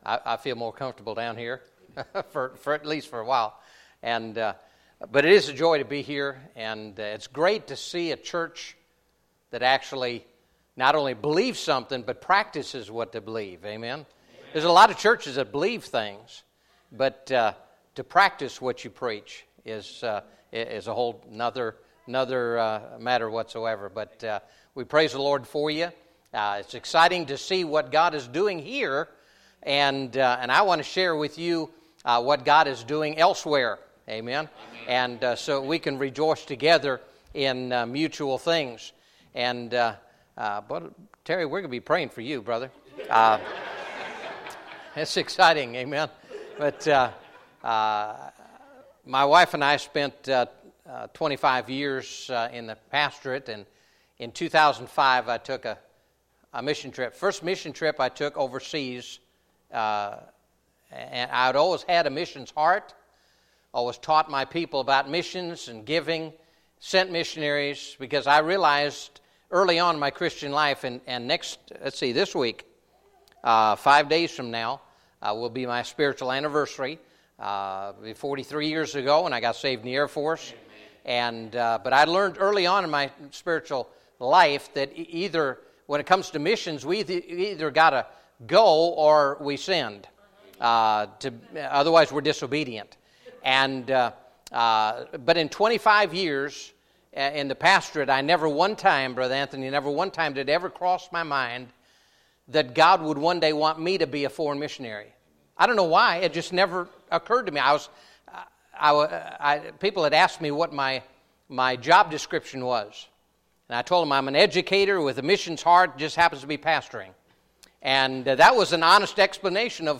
Solid Rock Baptist Church Sermons